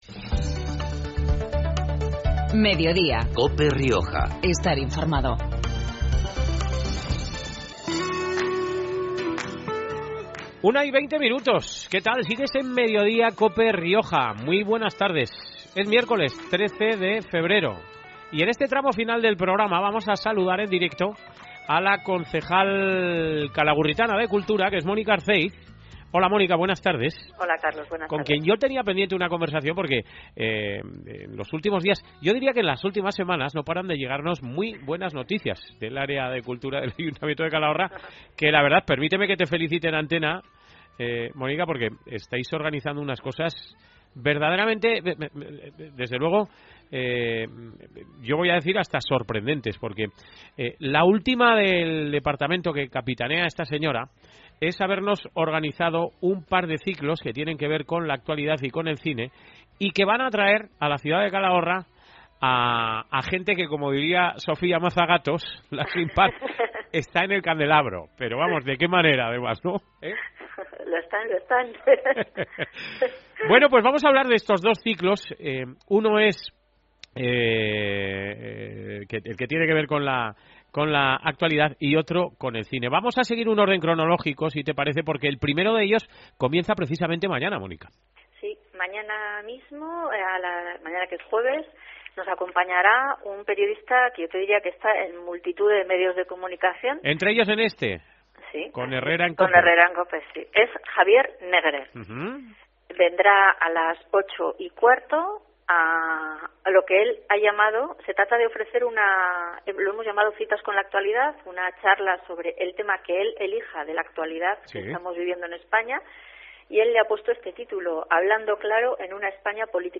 Herrera y Mediodía en Cope Rioja Baja (miércoles, 13 febrero). Hoy con Mónica Arcéiz, concejal de Cultura y Turismo del Ayuntamiento de Calahorra.
La concejal calagurritana de Cultura es Mónica Arcéiz y este mediodía explicaba el por qué de la organización de ambos ciclos.